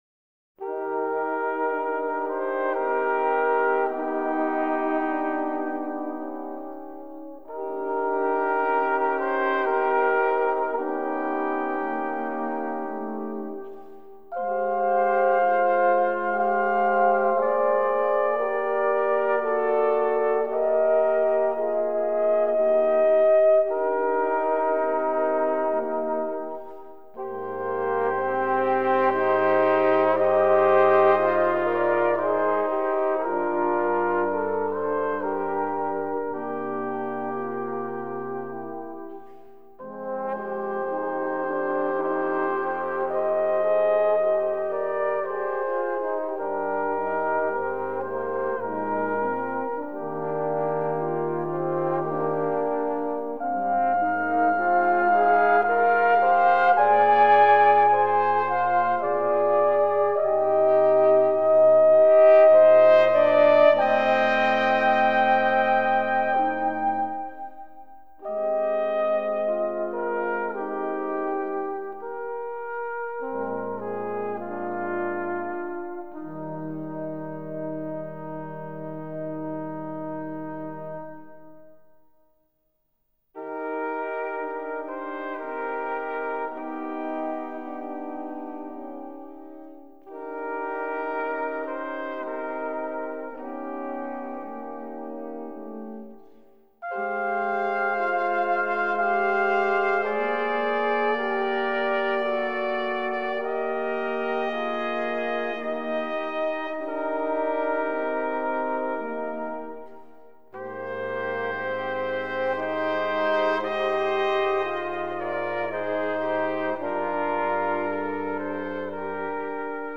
Trompettes
Trombones